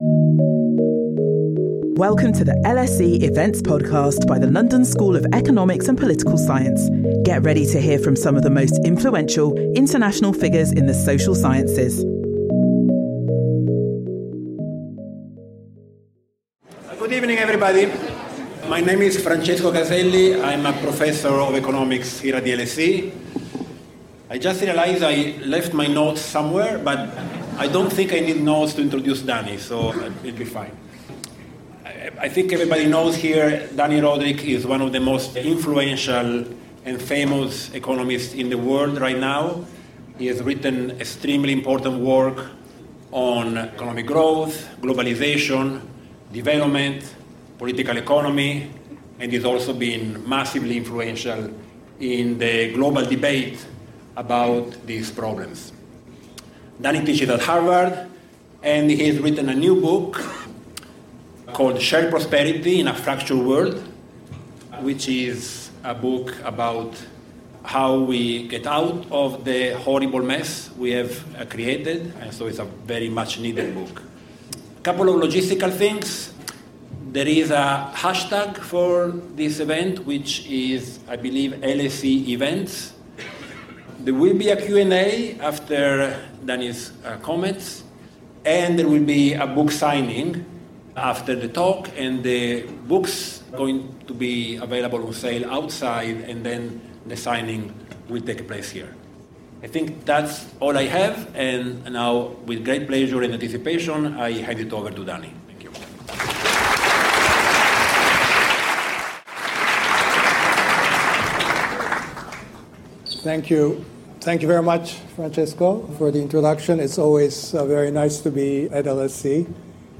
Join us for this talk by Dani Rodrik where he will talk about his new book, Shared Prosperity in a Fractured World, in which he shows how the nations of the world can achieve all three objectives.